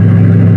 engine3.ogg